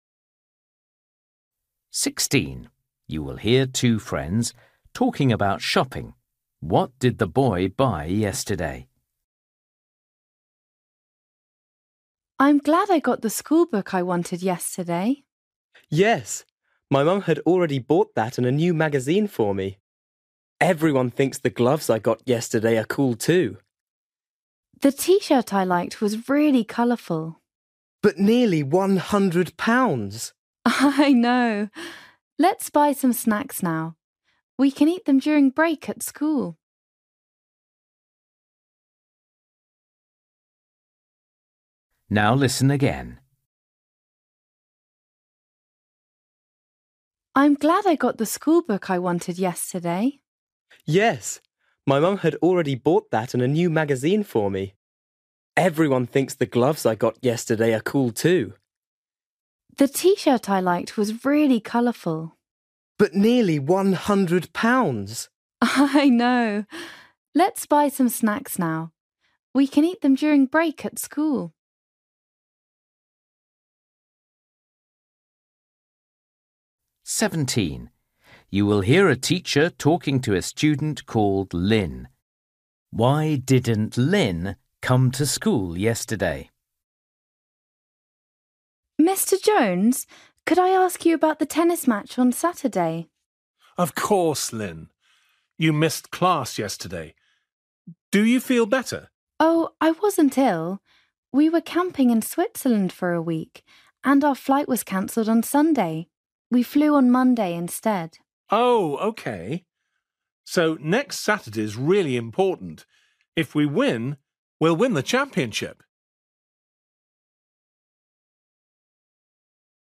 Listening: everyday short conversations
16   You will hear two friends talking about shopping. What did the boy buy yesterday?
18   You will hear a boy talking about surfing. How did he learn to surf?
19   You will hear a girl talking about her day at school. Which subject did she like best?